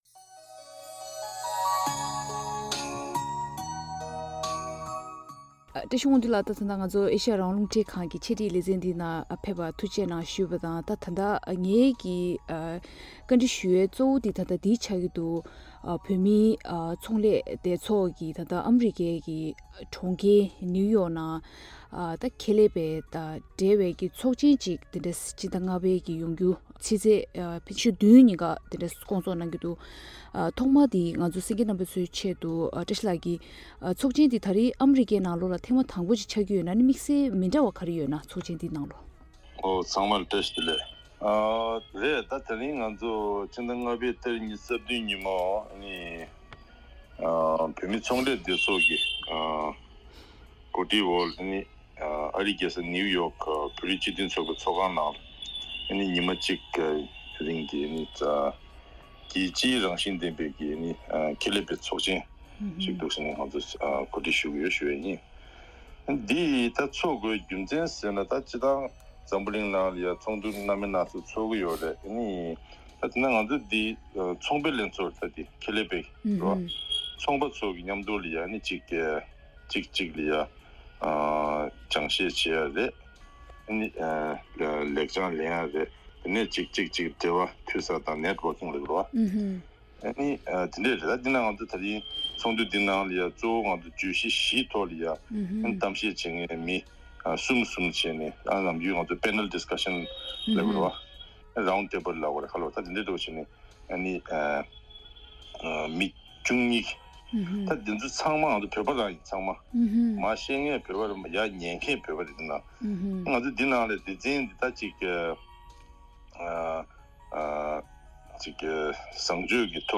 འདྲི་བ་དྲིས་ལན